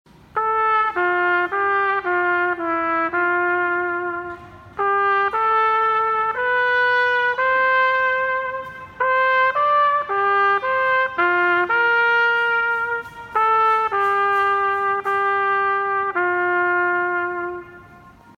Trumpet Solo